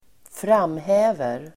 Uttal: [²fr'am:hä:ver]